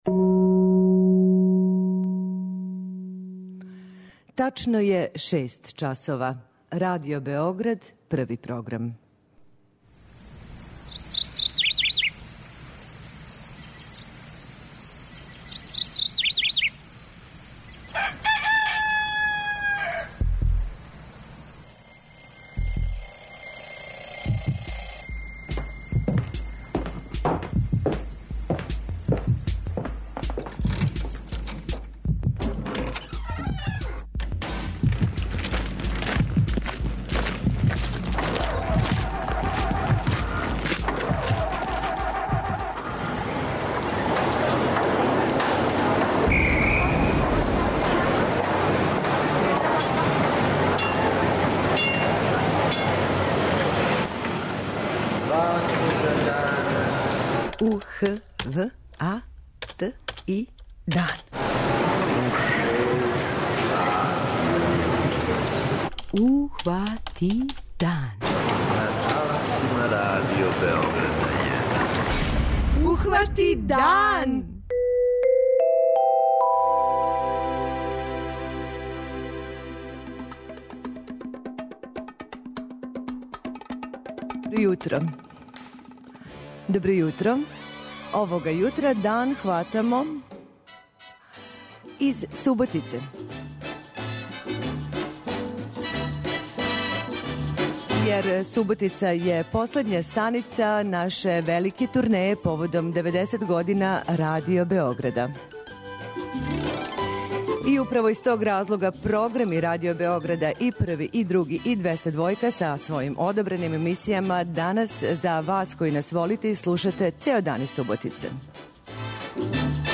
Овога јутра дан хватамо у Суботици која је последња станица велике турнеје Радио Београда под називом "Радио који се види", поводом деведесет година постојања наше куће.